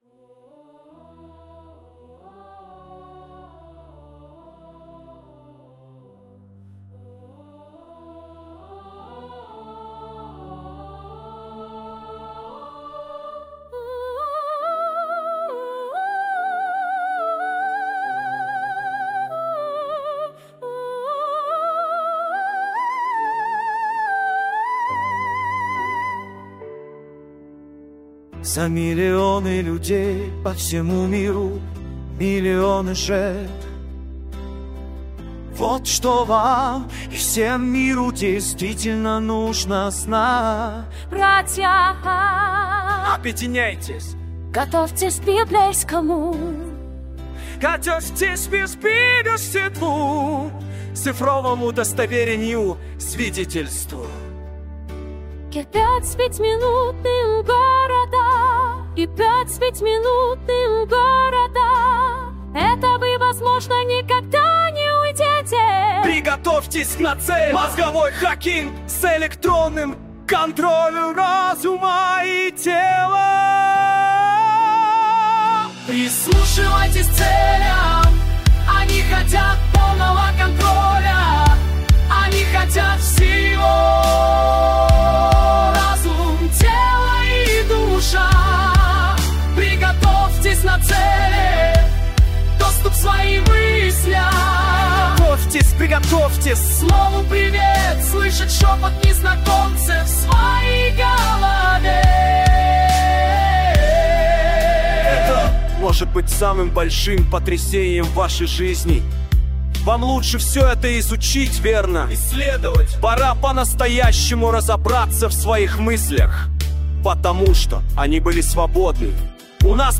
TI DAY RUSSIAN V1 ДЕНЬ ПРОТЕСТА — Хоровой Гимн